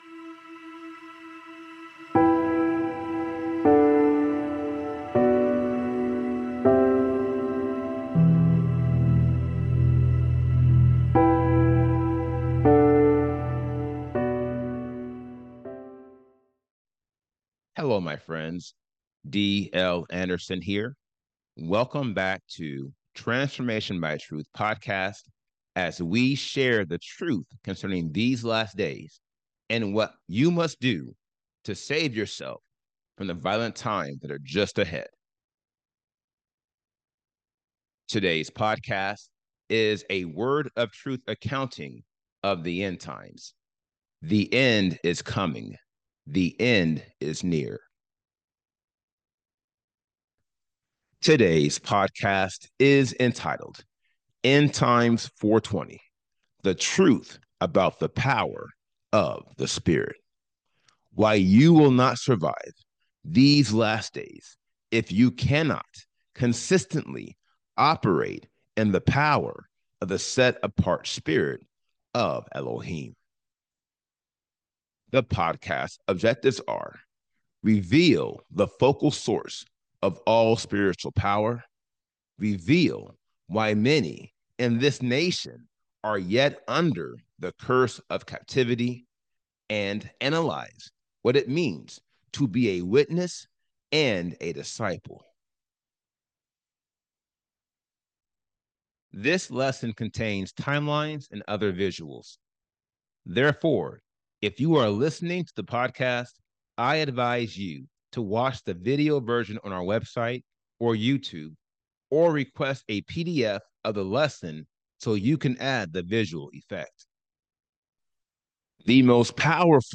This podcast is a 400-level lecture dedicated to analyzing the 80 degrees of lawlessness and showing you how you can eliminate each one to obtain the Seal of Elohim. Its purpose is to reveal the focal source of all spiritual power, reveal why many in this nation are yet under the “curse of captivity,” and analyze what it means to be a witness and a disciple.